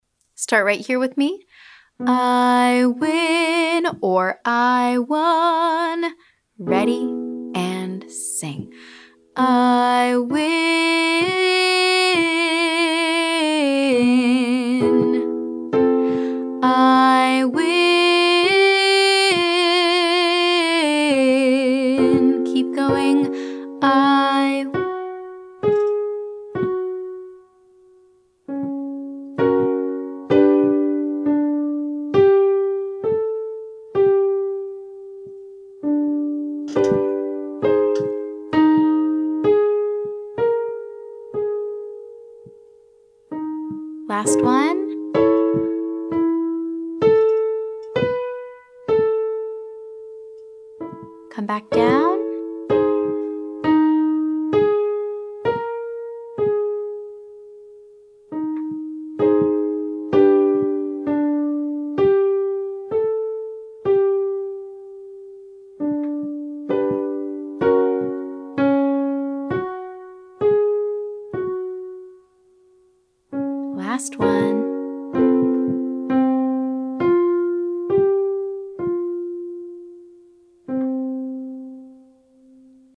Day 11: Chest-dominant Mix
This is our chest-dominant mix, the mode we typically use for belting.